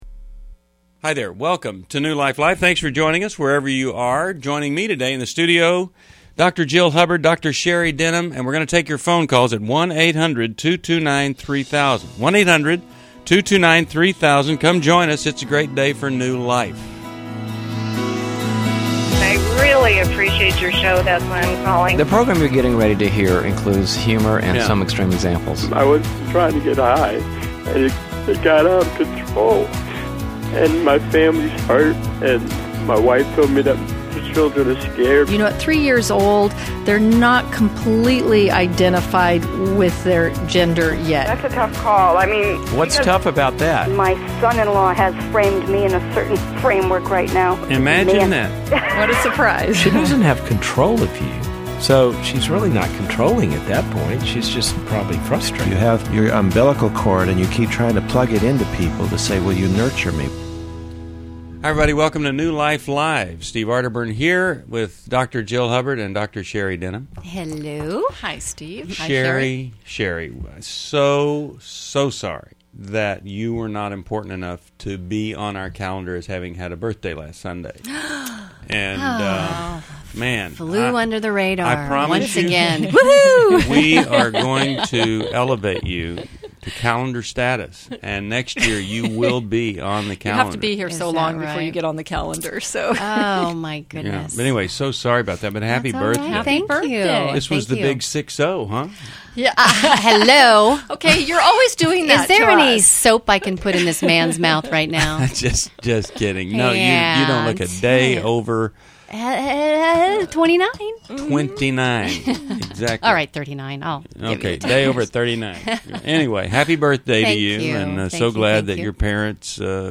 Caller Questions: My 17yo son may be smoking pot; should we do a urine test?